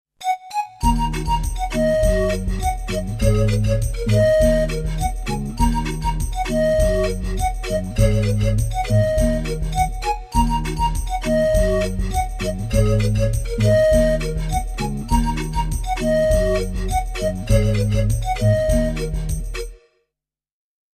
Roland S 550, Alesis MMT 8.